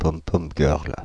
Ääntäminen
France (Paris): IPA: /pɔm.pɔm ɡœʁl/